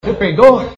peidou
peidou.mp3